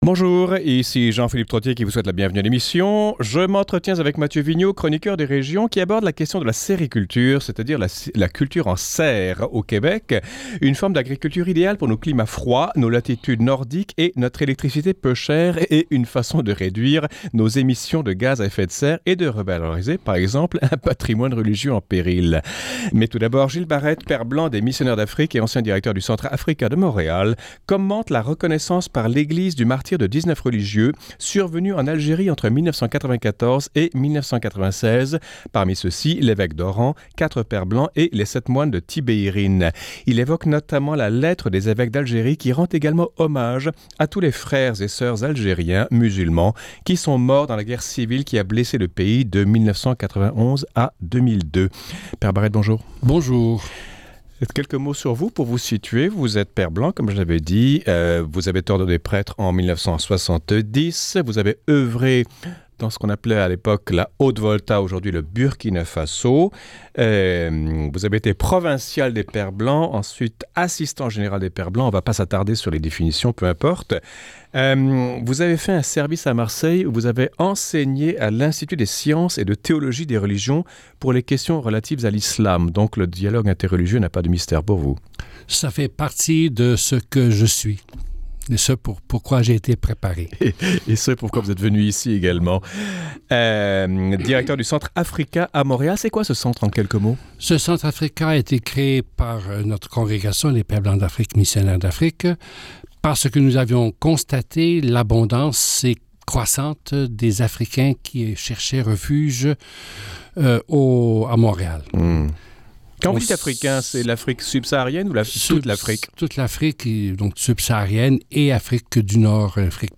Quelques extraits de l’entrevue :